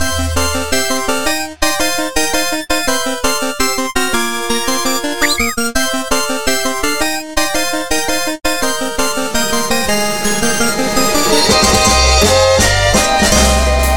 grimmfanfare.mp3